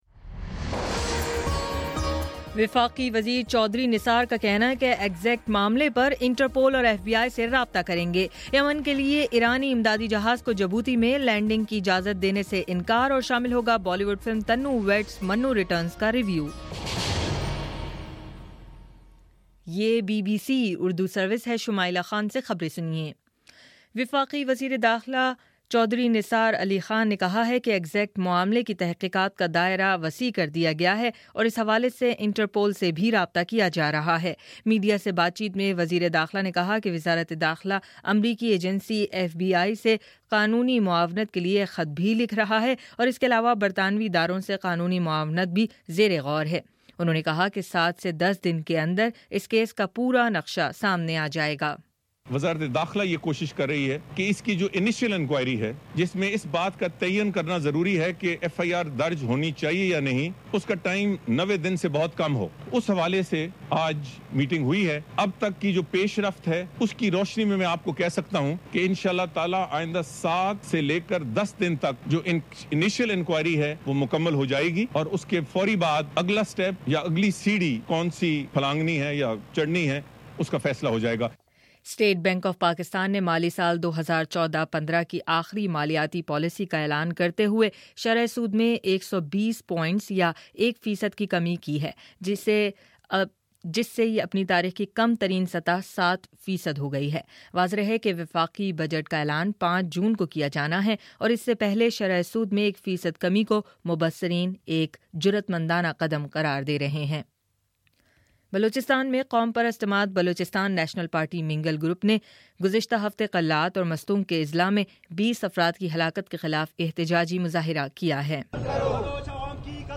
مئی 23: شام سات بجے کا نیوز بُلیٹن